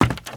STEPS Wood, Creaky, Run 15.wav